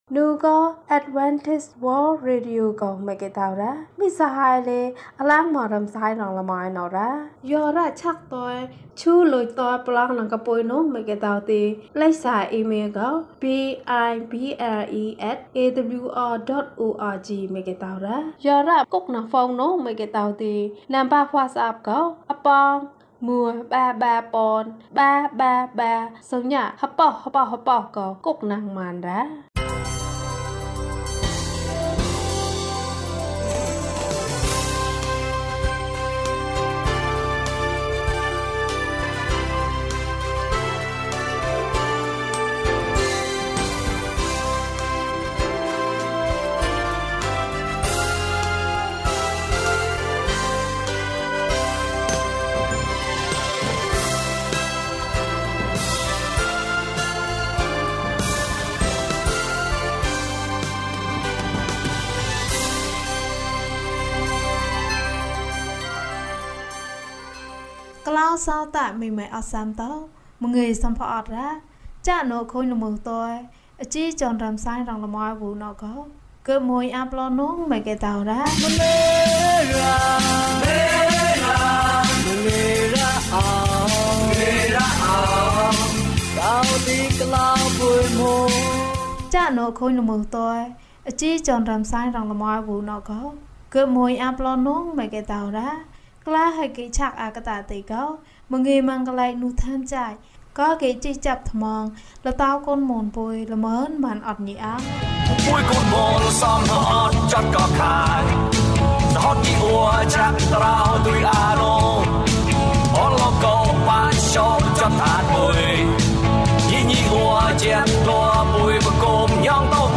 ကျန်းမာရေး နှင့် ပုံပြင်။ ကျန်းမာခြင်းအကြောင်းအရာ။ ဓမ္မသီချင်း။ တရားဒေသနာ။